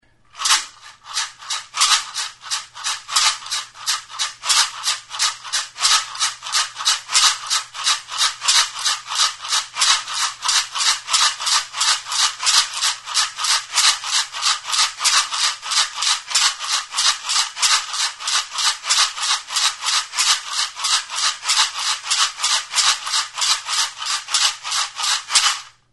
Instrumentos de músicaMaraka; Sonajeroa
Idiófonos -> Golpeados -> Maracas / sonajeros
Grabado con este instrumento.
Barrutik hutsik dauden oso porosoak diren zurezko 5 makila dira, elkarri erantsiak. Astintzerakoan barruan dituzten hazi aleek hotsa ematen dute.